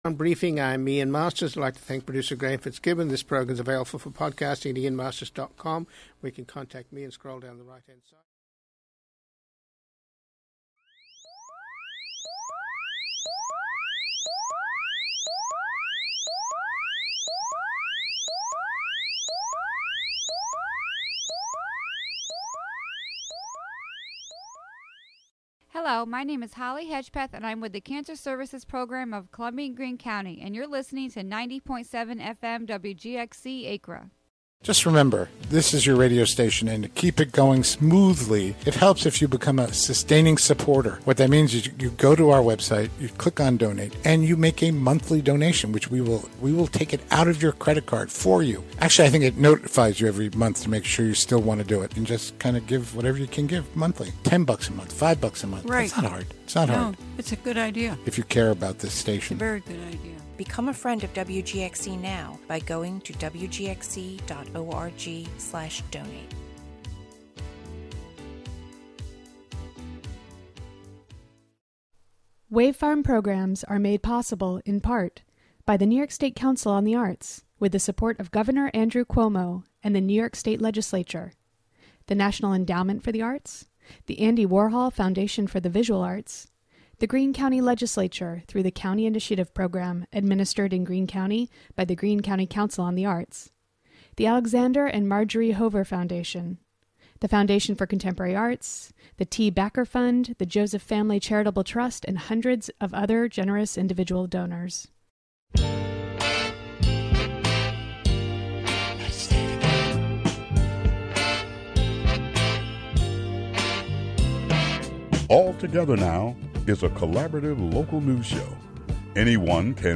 Hear an excerpt from Monday's Youth Incarceration in the Hudson Valley presentation at the Hudson Library, plus an interview with the local Citizen Action chapter from The Sanctuary for Independent Medi's WOOC-LP. "All Together Now!" is a daily news show brought to you by WGXC-FM in Greene and Columbia counties.